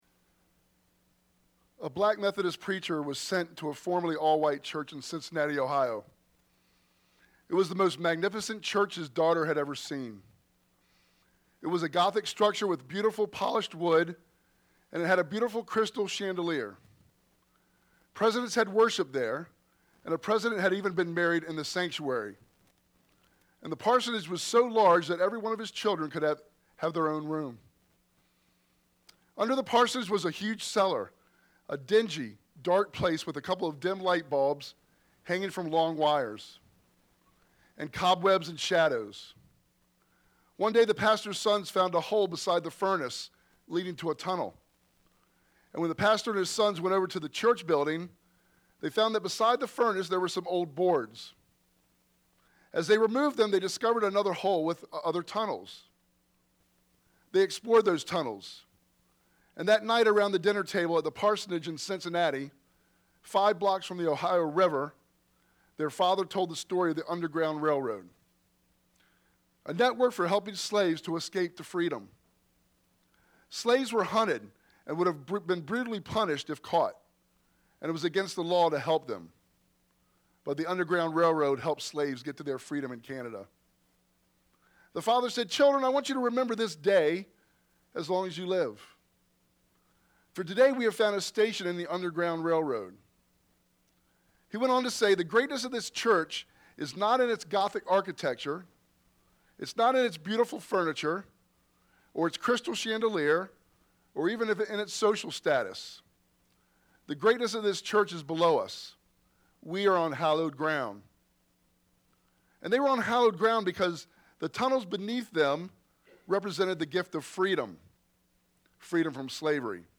First Things First Idaville Church » Sermons podcast